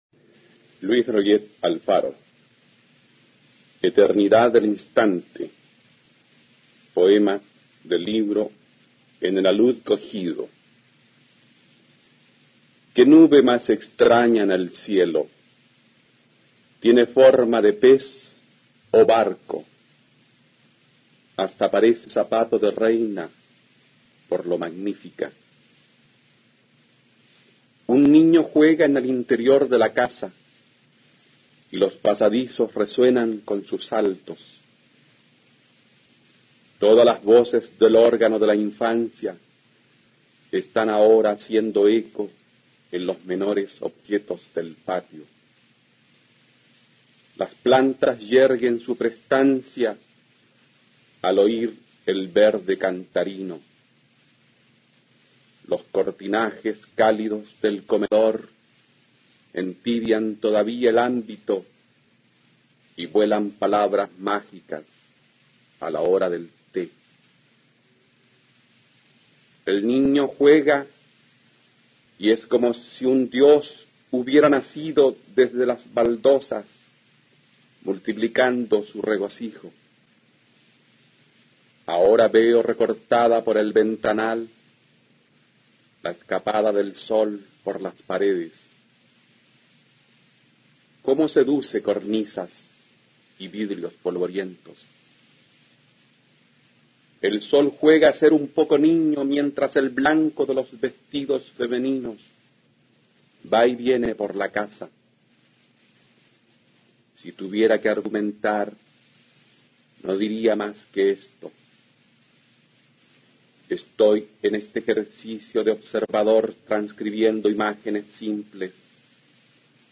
Poesía
Poema